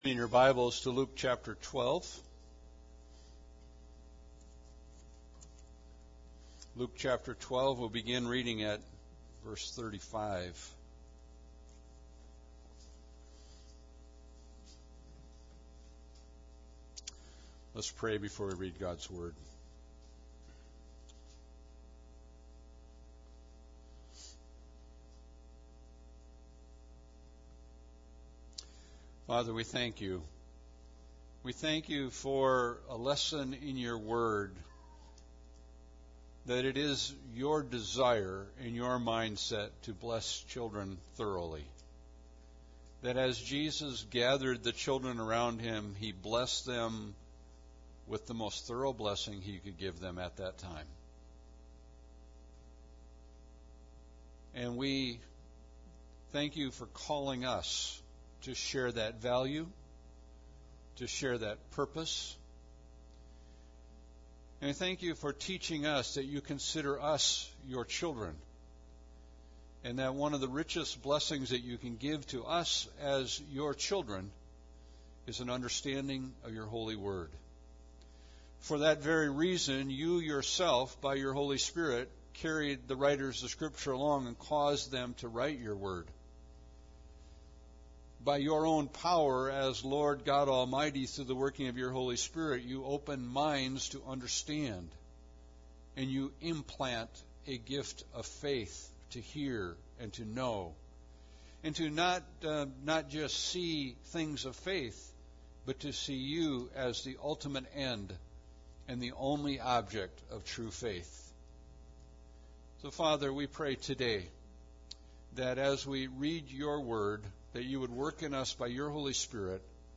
Passage: Luke 12:35-59; Luke 13:1-9 Service Type: Sunday Service